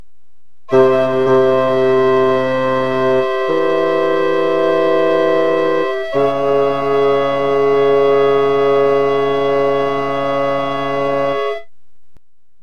Now let’s do a sudden modulation from C Major to D1b Minor:
C – E1 – G    to   D1b – F2b – A1b Figure 13-3b with sound  [bass line C e1 D1b]
53-figure-13-3b-great-diesis-modulation.mp3